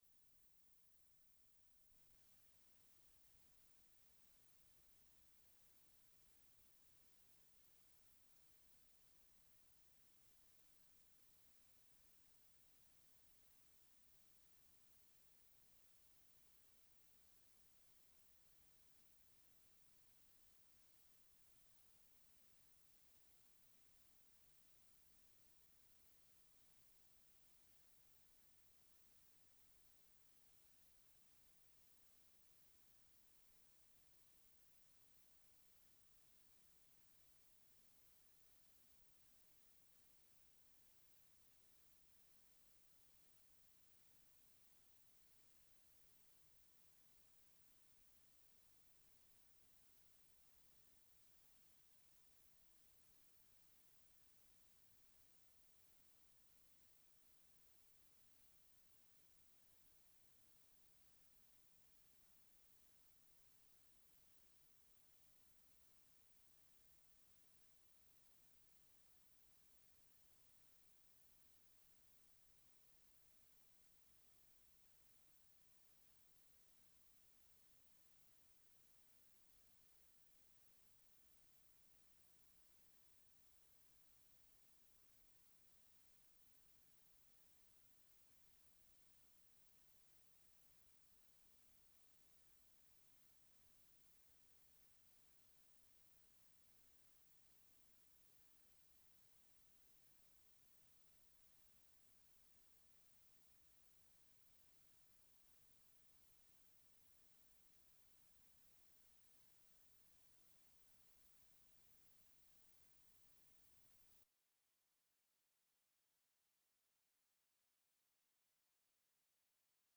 Interview
He employed a cassette recorder to record the interviews.